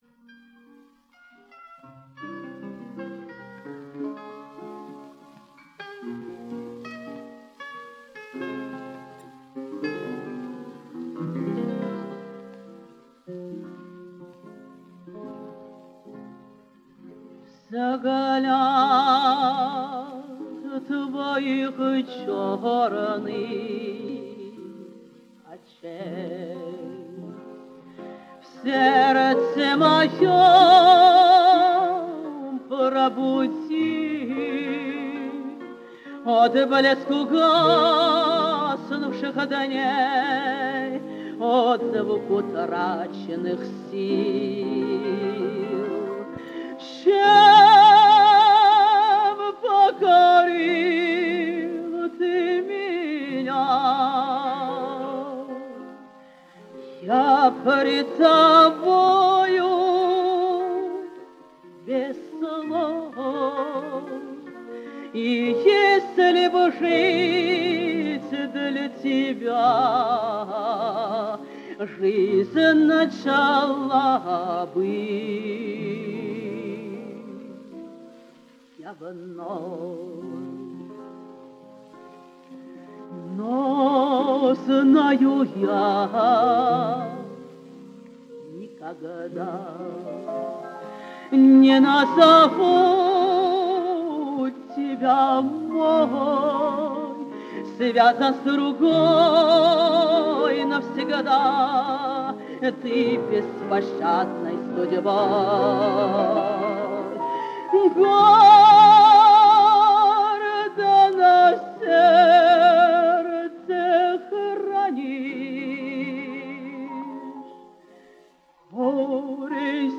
Жанр: Романсы